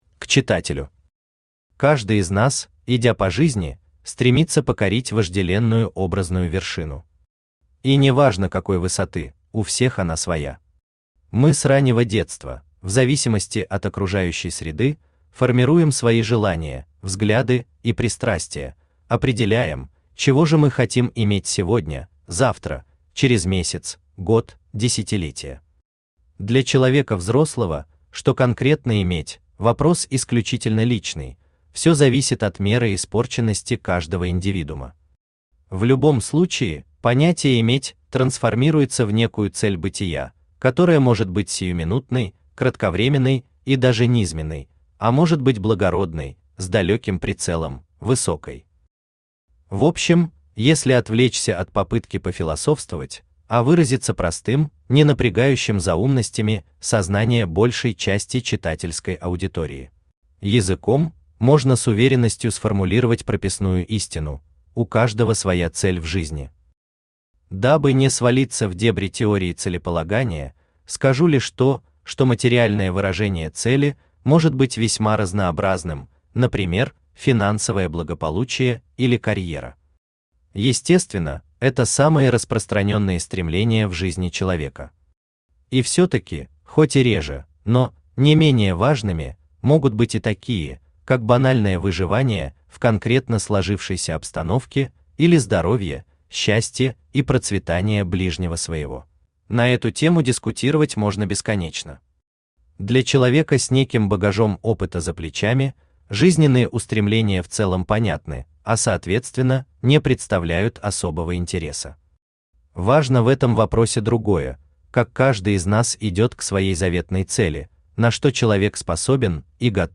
Аудиокнига На пути к звёздам. Исповедь тылового генерала.
Сборник рассказов Автор Виктор Владимирович Беник Читает аудиокнигу Авточтец ЛитРес.